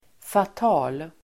Ladda ner uttalet
Uttal: [fat'a:l]